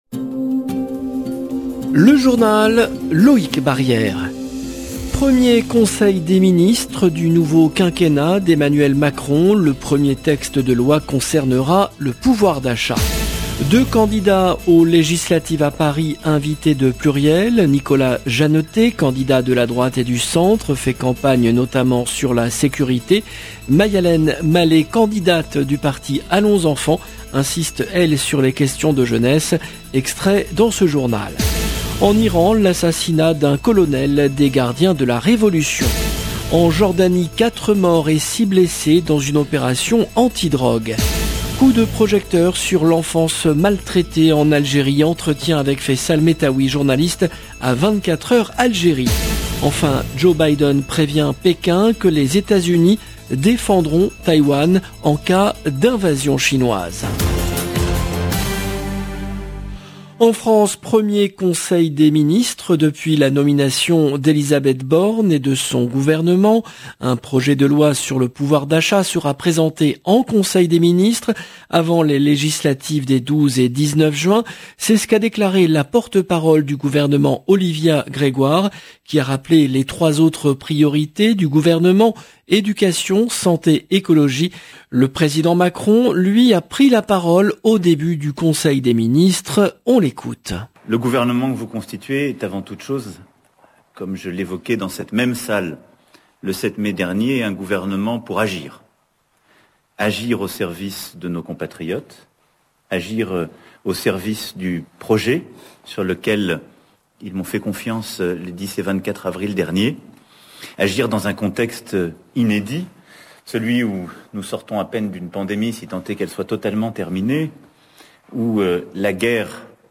LE JOURNAL DU SOIR EN LANGUE FRANCAISE DU 23/05/22